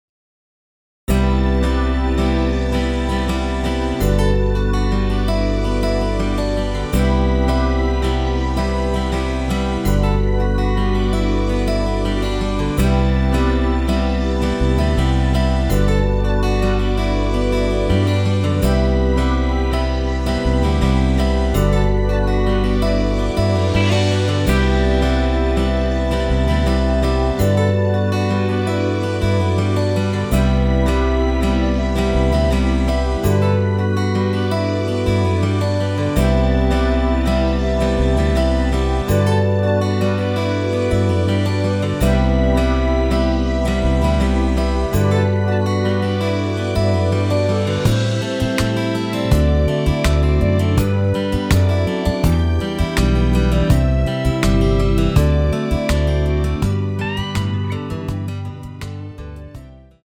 엔딩이 페이드 아웃이라 라이브 하시기 좋게 엔딩을 만들어 놓았습니다.